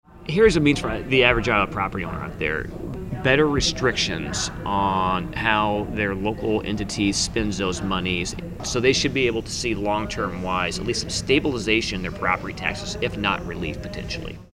Reynolds made her comments during a recent interview with Radio Iowa.